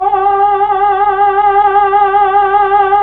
Index of /90_sSampleCDs/Roland LCDP09 Keys of the 60s and 70s 1/KEY_Chamberlin/VOX_Chambrln Vox